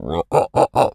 lizard_taunt_emote_04.wav